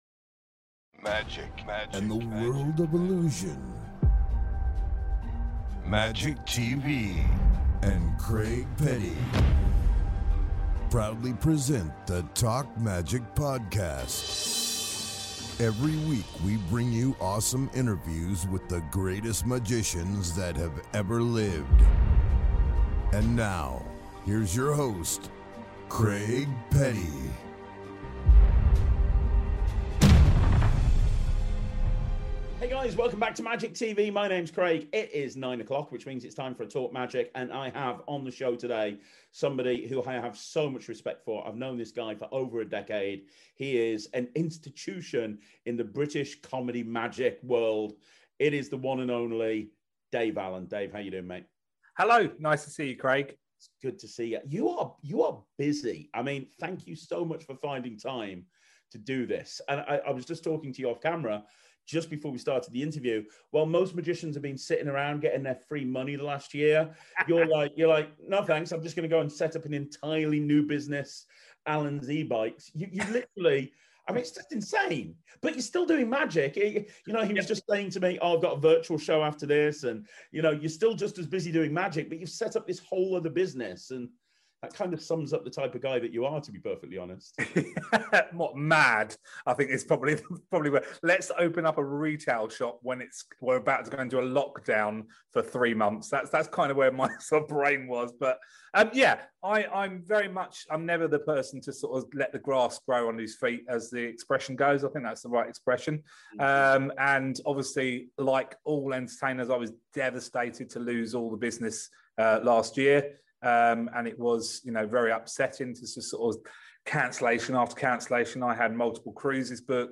This is an interview you don't want to miss.